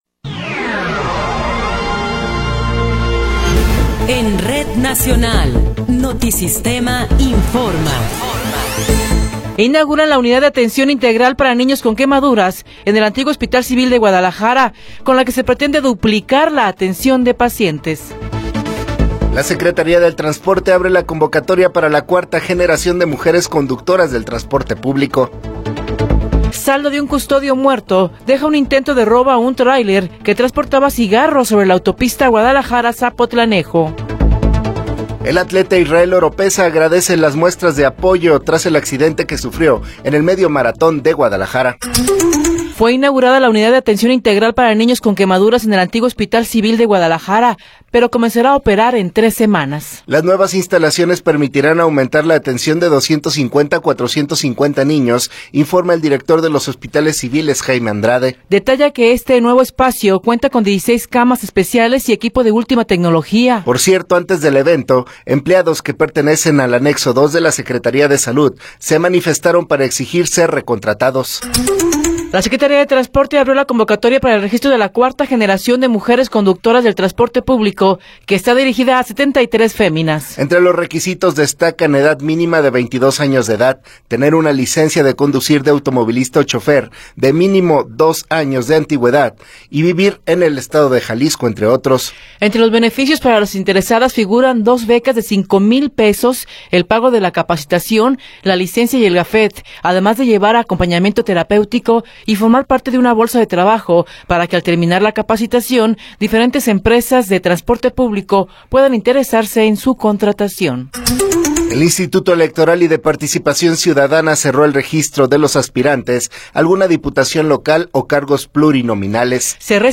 Noticiero 9 hrs. – 27 de Febrero de 2024
Resumen informativo Notisistema, la mejor y más completa información cada hora en la hora.